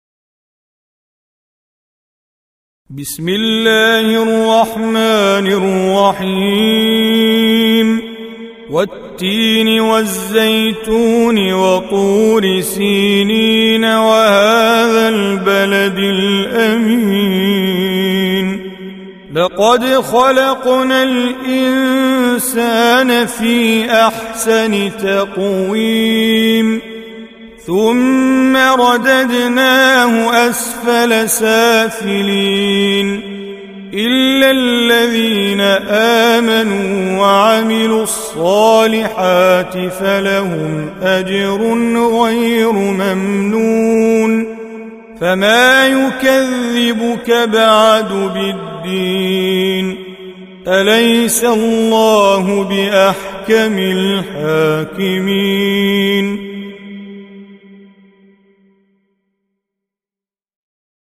95. Surah At-Tin سورة التين Audio Quran Tajweed Recitation
Surah Repeating تكرار السورة Download Surah حمّل السورة Reciting Mujawwadah Audio for 95.